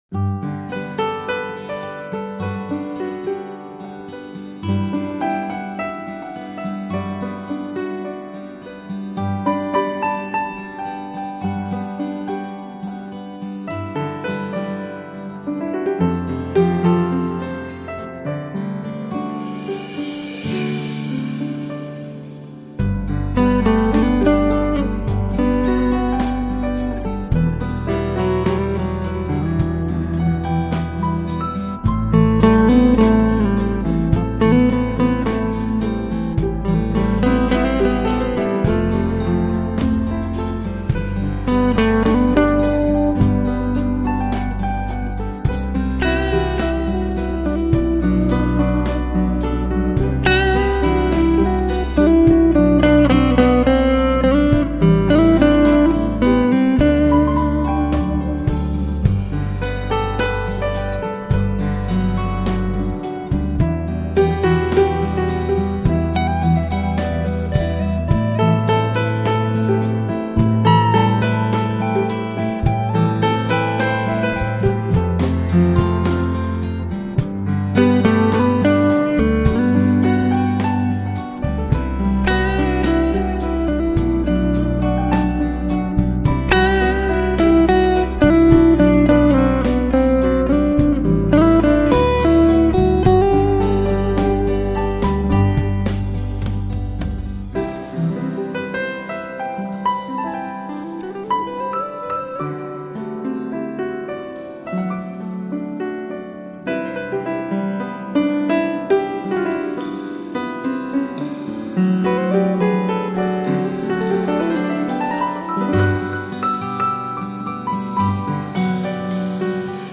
捉黠地懸在吉他弦間、輕巧地落在鋼琴鍵上、神秘地躲在薩克管裡、
頑皮地跳躍在鼓上……
悠閒放肆地彈奏出狂野的奇幻、抒情溫柔地吹奏著神秘的浪漫！